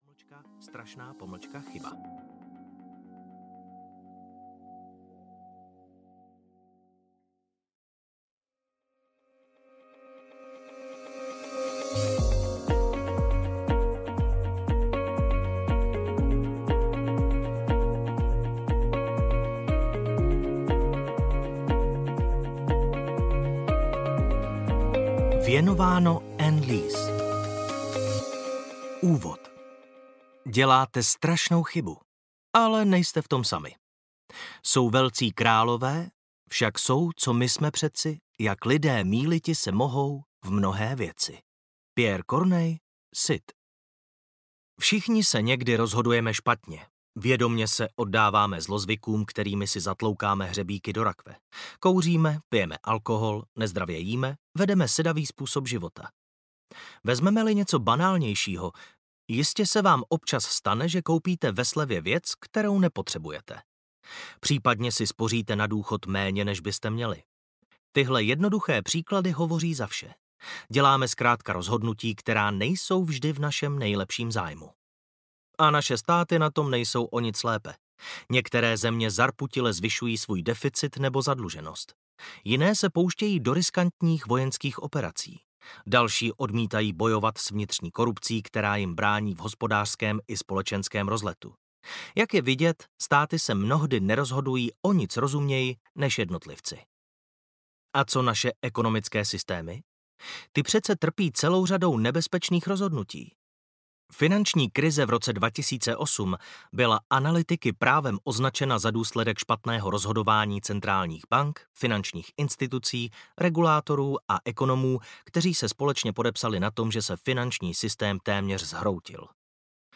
Chystáte se udělat strašnou chybu! audiokniha
Ukázka z knihy